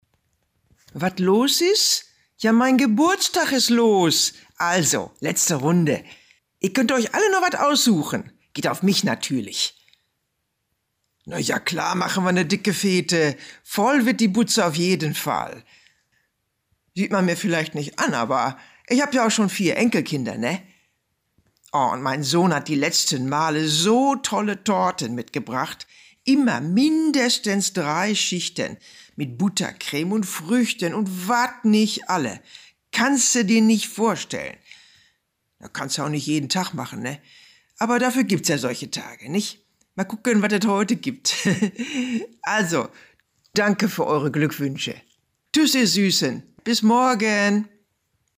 markant
Alt (50-80)
Audio Drama (Hörspiel), Comedy, Scene
Ruhrgebiet